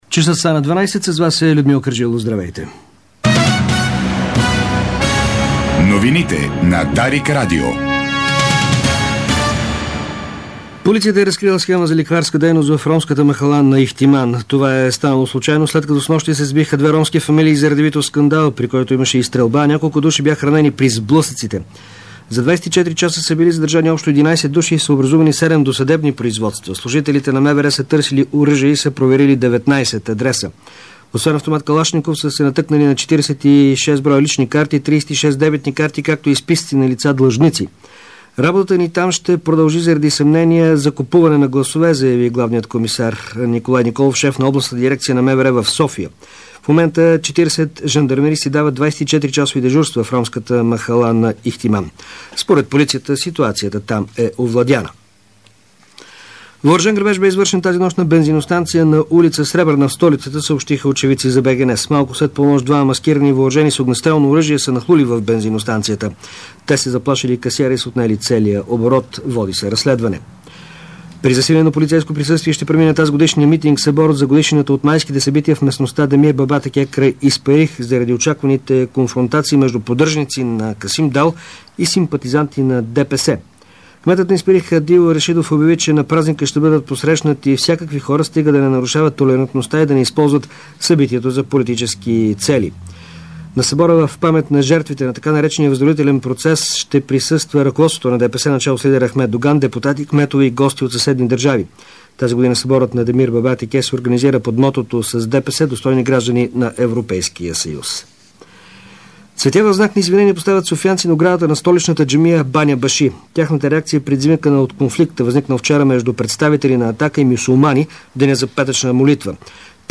Обедна информационна емисия - 21.05.2011